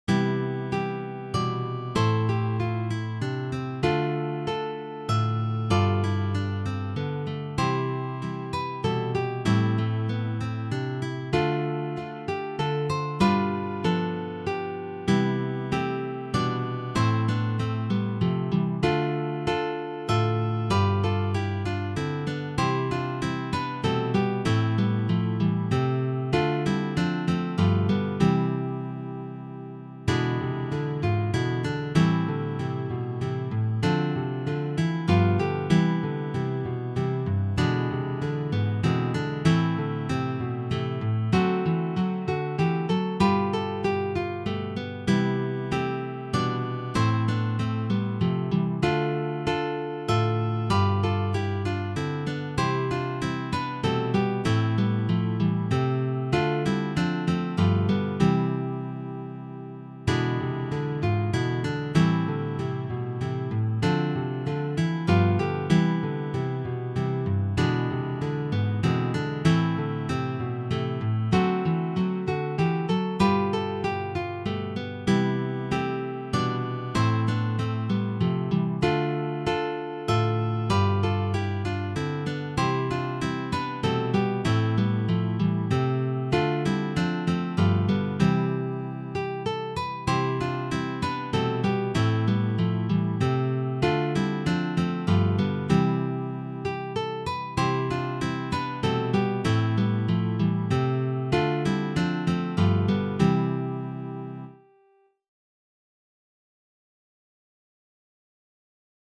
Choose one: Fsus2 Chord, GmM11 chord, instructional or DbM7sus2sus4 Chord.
instructional